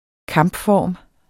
Udtale [ ˈkɑmbˌfɒˀm ]